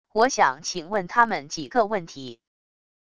我想请问他们几个问题wav音频生成系统WAV Audio Player